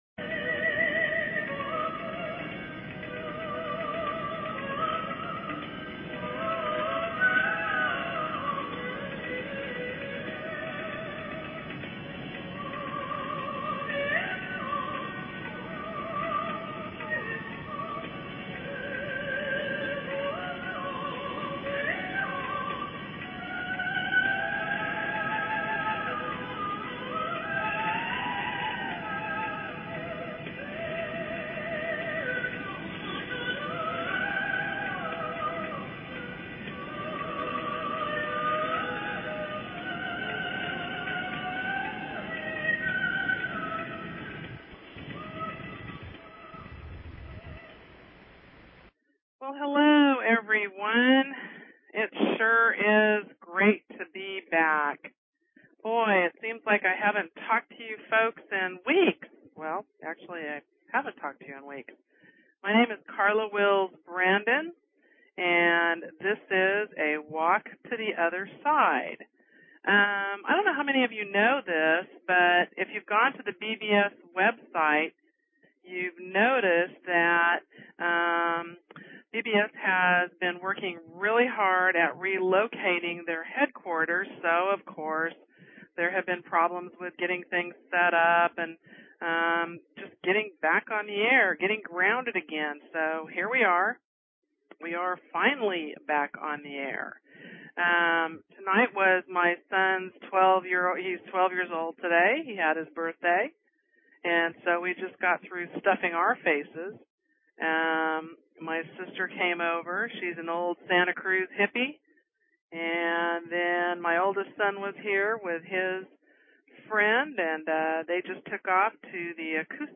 Talk Show Episode, Audio Podcast, A_Walk_To_Otherside and Courtesy of BBS Radio on , show guests , about , categorized as